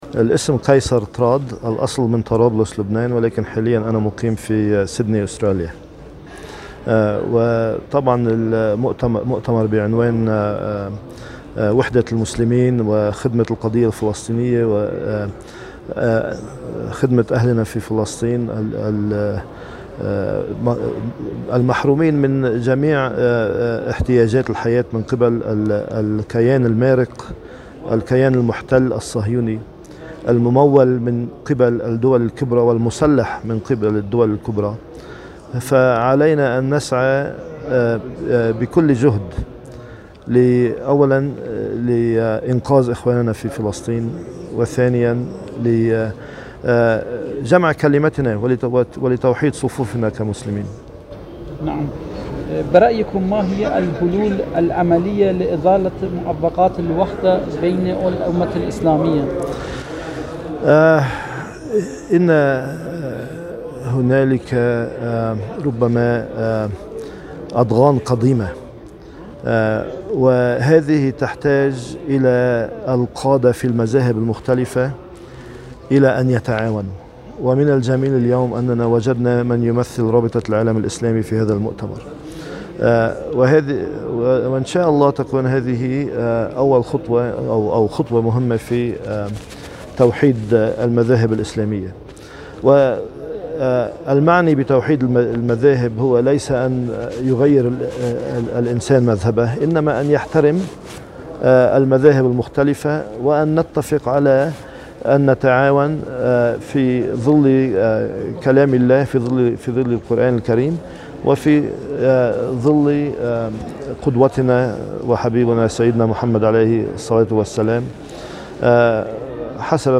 في حوار خاص له مع وكالة الأنباء القرآنية الدولية(إکنا) على هامش مشاركته في المؤتمر الدولي للوحدة الإسلامية بدورته الـ38 في العاصمة الايرانیة طهران.